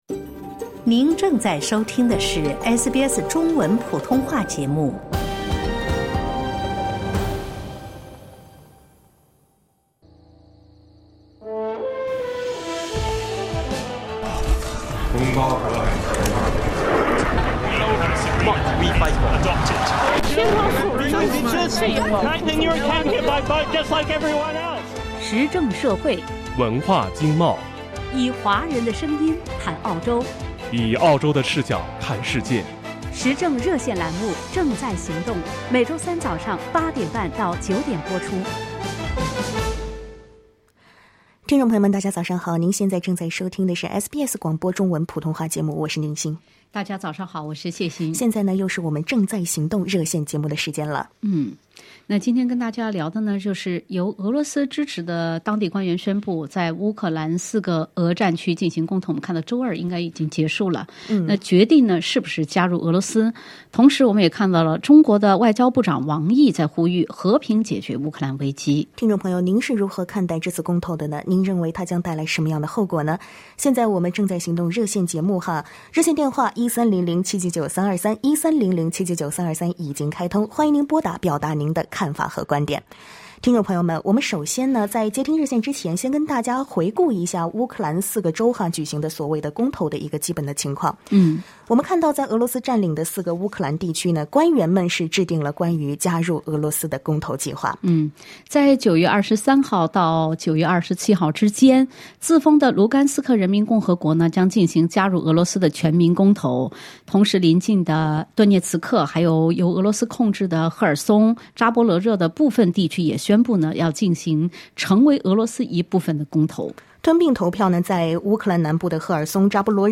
在节目中，听友们还就公投的后果、国际社会的反应、中国等国的态度等话题发表了看法。